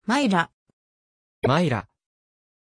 Pronunția numelui Mylah
pronunciation-mylah-ja.mp3